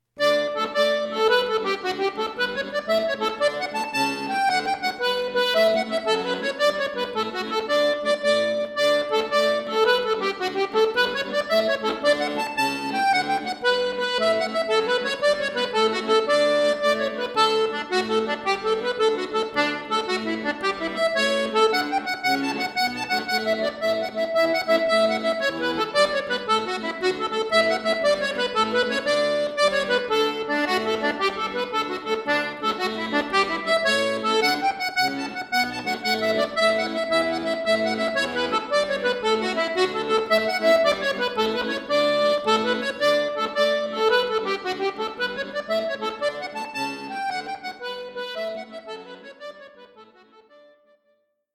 Folk, Irisch, Klassisch